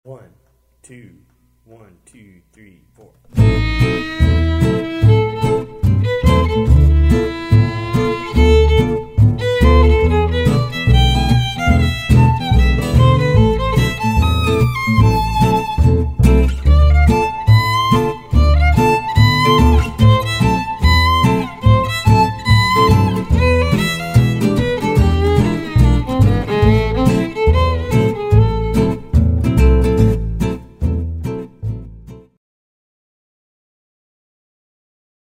-Slides y notas de adorno: